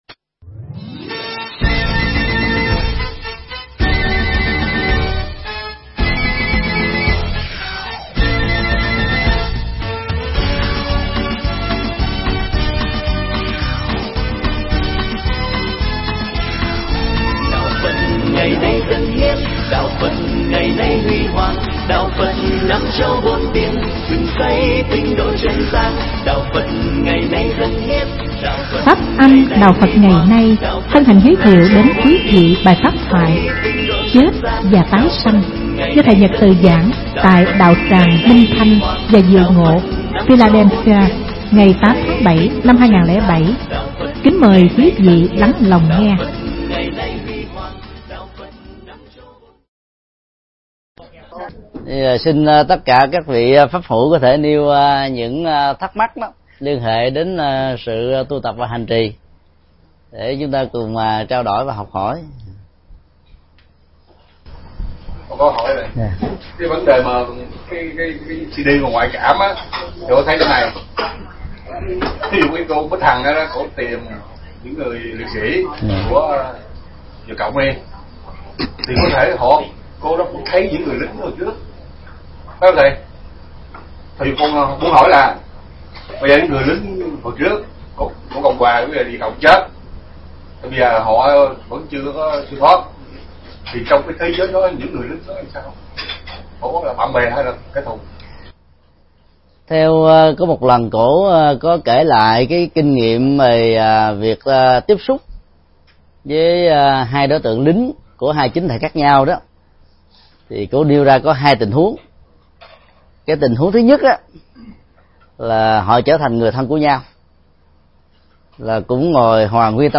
Mp3 Pháp Thoại Chết và tái sinh
giảng tại Đạo Tràng Minh Thanh Và Diệu Ngộ Philadelphia Hoa Kỳ